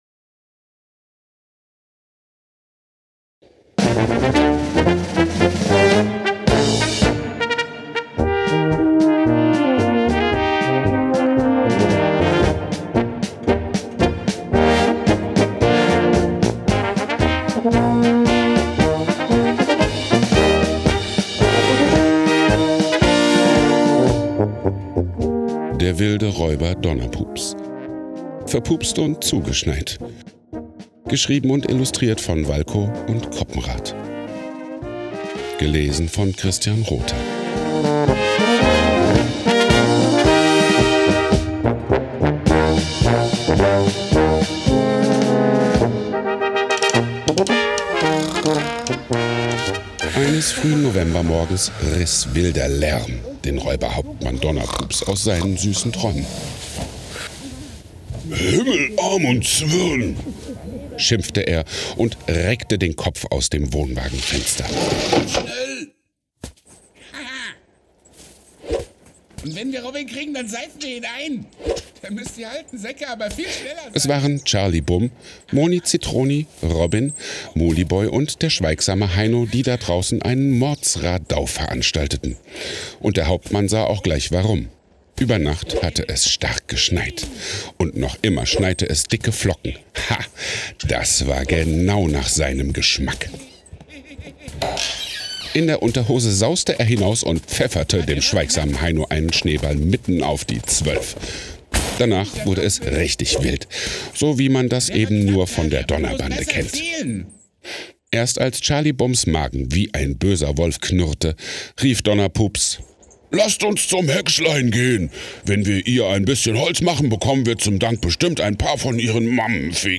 Hörspiel zum Vorlesezelt
In diesem Jahr durften die Kinder wieder einer weiteren schönen Weihnachtsgeschichte im Vorlesezelt beim Uelversheimer Baumschmücken lauschen.